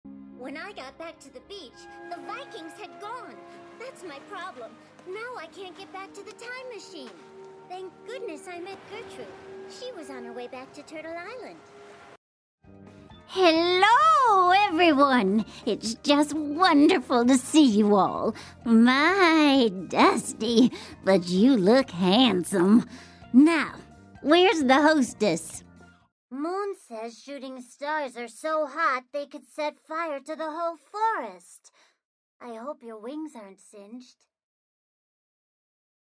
dunkel, sonor, souverän, plakativ
Mittel minus (25-45)
Presentation